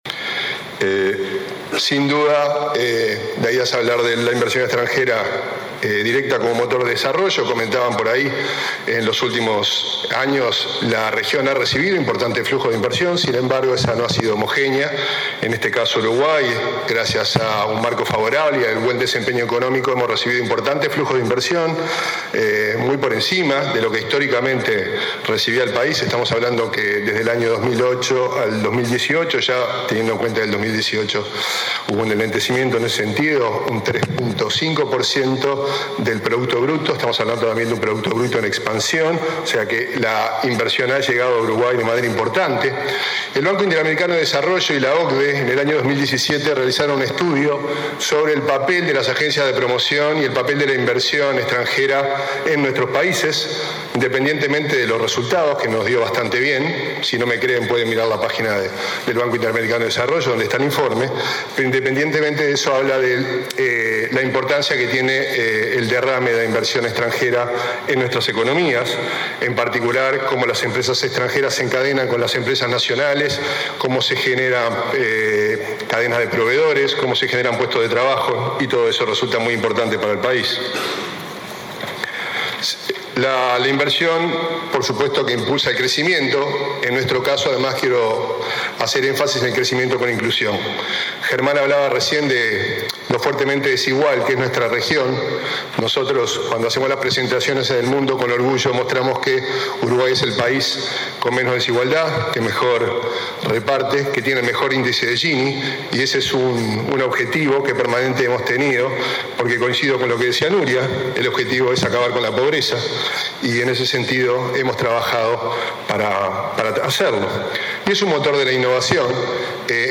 Carámbula destacó desarrollo de servicios globales que exportan por más de US$ 3.000 millones y emplean a 20.000 personas 20/06/2019 Compartir Facebook Twitter Copiar enlace WhatsApp LinkedIn El director de Uruguay XXI, Antonio Carámbula, disertó este jueves en el Encuentro de Convergencia Empresarial que se realiza en Montevideo. Habló de buenas prácticas en la atracción de inversión extranjera directa y del desarrollo de servicios globales como las TIC y los servicios corporativos, que exportan por US$ 3.000 millones y emplean a unas 20.000 personas. Dijo que Uruguay tiene “antenas comerciales” en seis ciudades.